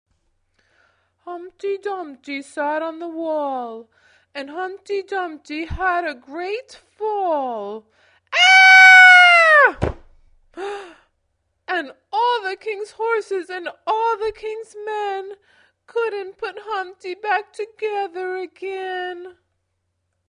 Humpty Dumpty - English Children's Songs